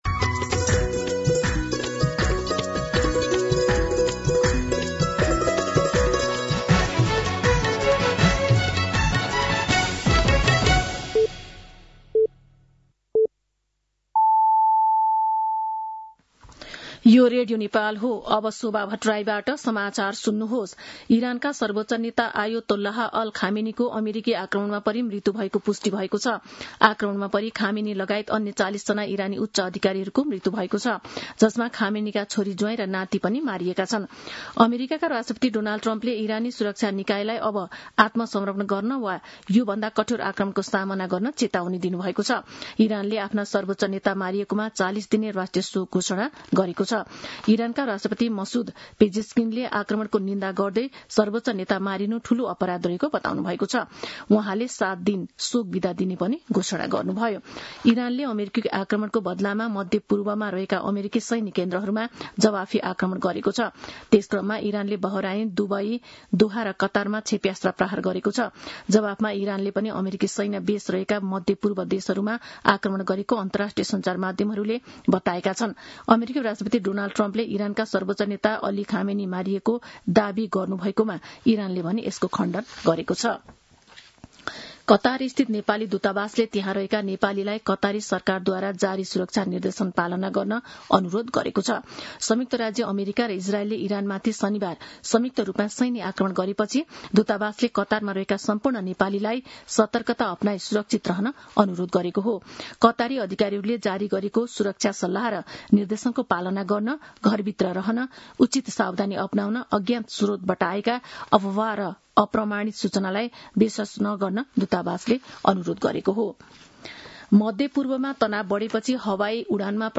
दिउँसो ४ बजेको नेपाली समाचार : १७ फागुन , २०८२
4pm-News-17.mp3